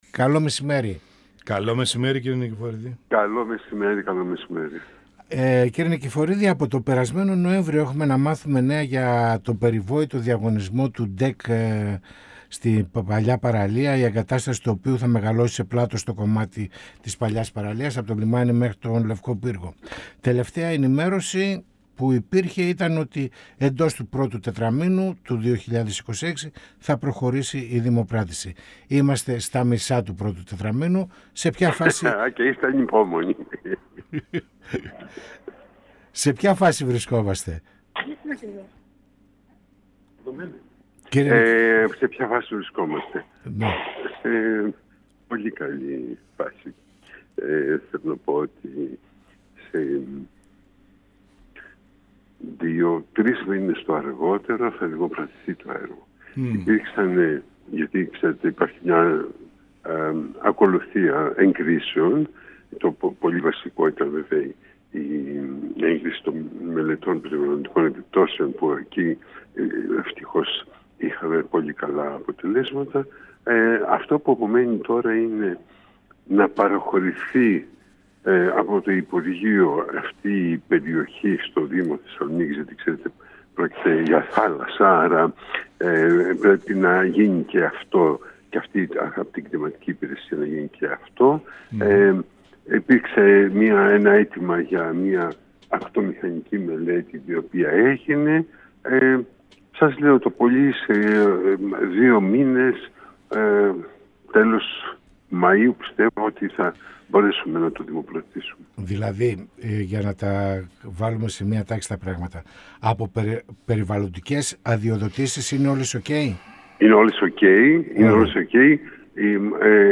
Προχωρά με γοργούς ρυθμούς η υλοποίηση της ιδέας του δήμου Θεσσαλονίκης για την κατασκευή του ξύλινου ντεκ στην Παλιά Παραλία, που θα δίνει τη δυνατότητα σε κατοίκους, επισκέπτες αλλά και ποδηλάτες, να απολαμβάνουν άνετα τη βόλτα τους στο παραλιακό μέτωπο με θέα τον Θερμαϊκό Κόλπο, καθώς και στα κάγκελα που τοποθετήθηκαν, παρά την έντονη αντίδραση του Δημάρχου Θεσσαλονίκης στο Γαλεριανό Συγκρότημα αρχαιοτήτων αναφέρθηκε o Αντιδήμαρχος Τεχνικών Έργων του Δήμου Θεσσαλονίκης Πρόδρομος Νικηφορίδης, μιλώντας στην εκπομπή «Πανόραμα Επικαιρότητας» του 102FM της ΕΡΤ3.
Συνεντεύξεις